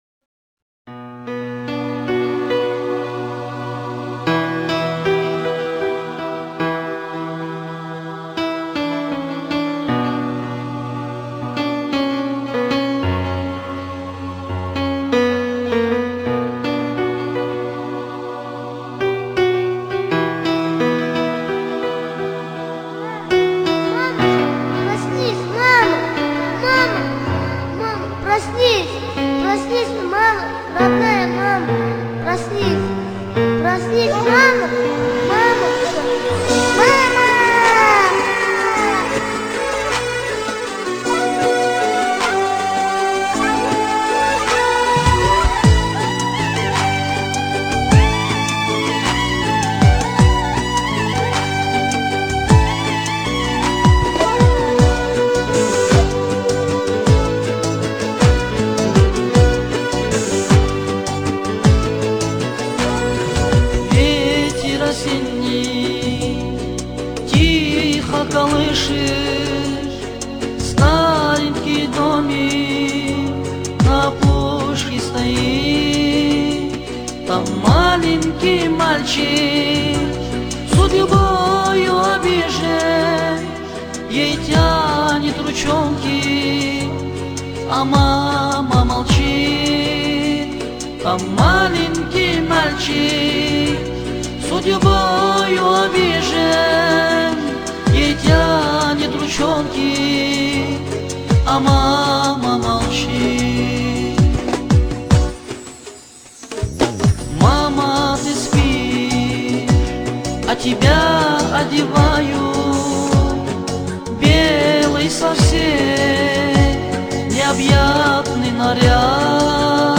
Всі мінусовки жанру Pop
Плюсовий запис